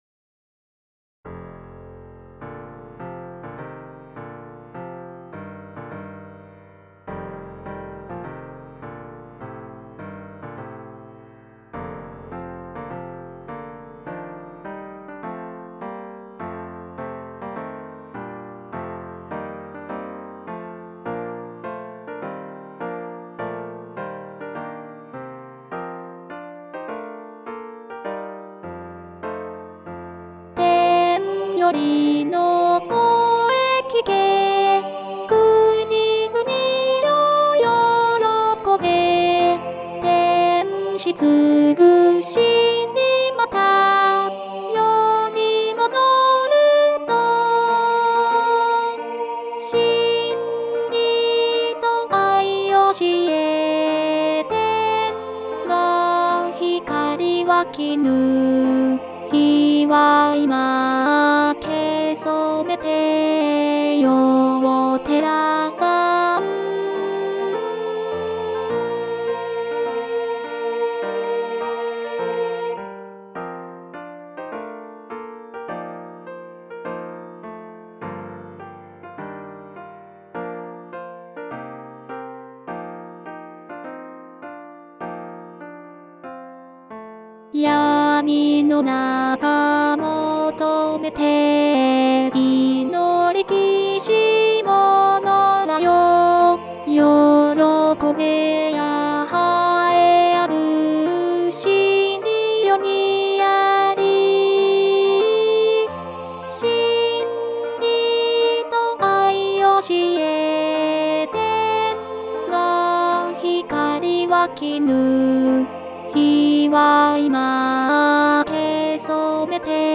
アルト（フレットレスバス音）